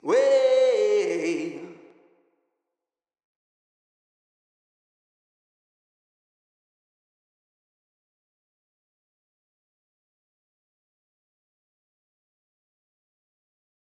Vocal Zion 2.wav